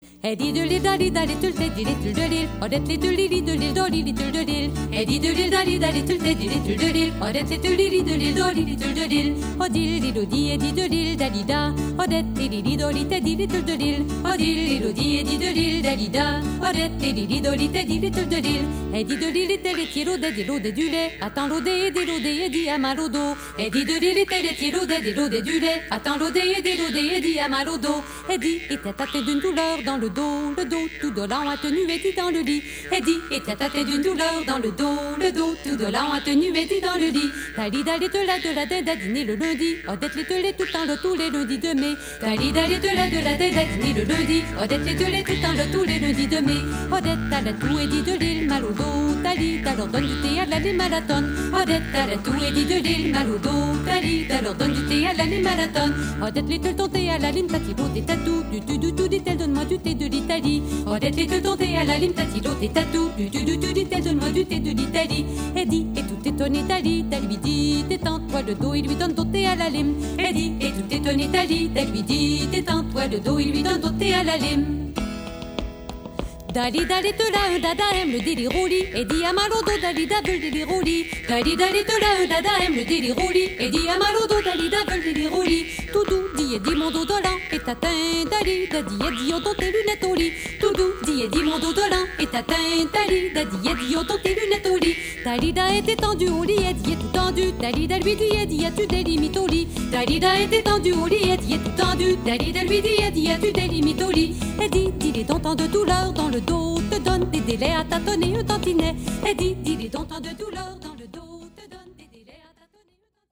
13 chansons traditionnelles québécoises
voix, guitares, guimbardes, harmonica
violon
flûte, cornemuse
voix, pieds, cuillères